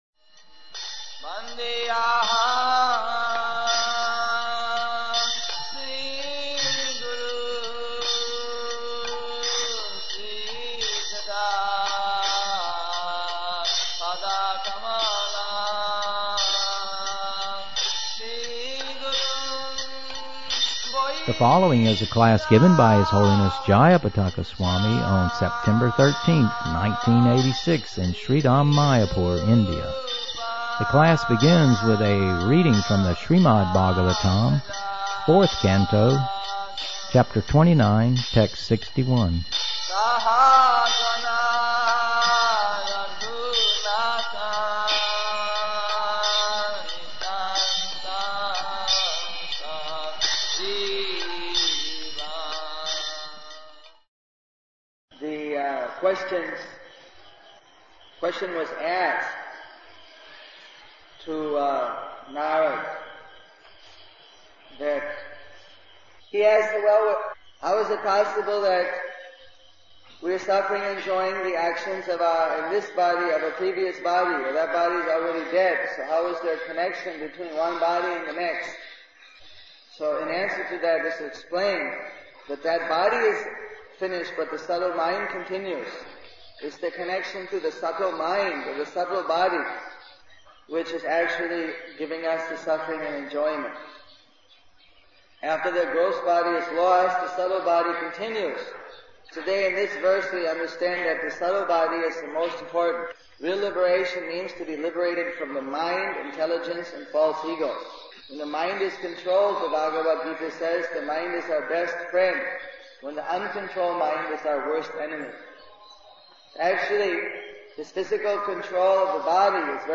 Lectures
The class begins with a reading from SB 4.29.61) The questions, question was asked to Narada that he has revolved.... how is it possible that we are suffering and enjoying the actions of ourthisbody and the previous body.